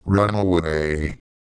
Worms speechbanks
runaway.wav